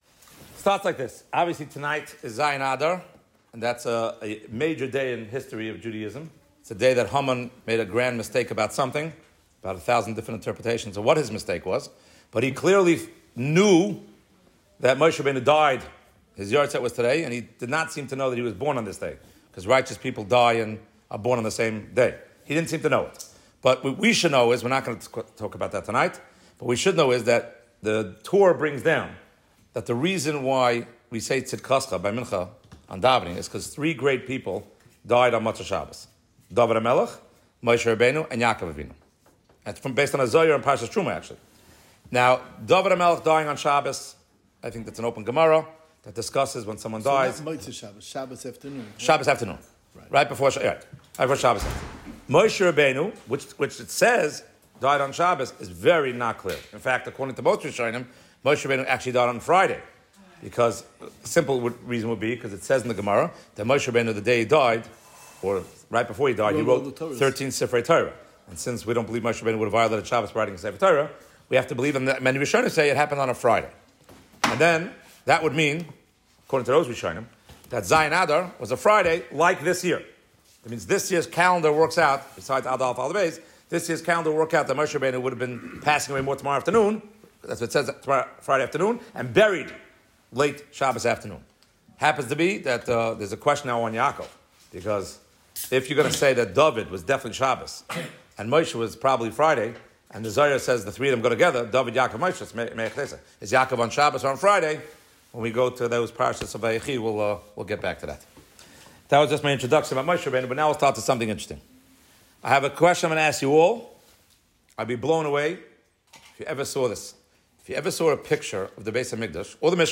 From Heimish shul of Houston, TX.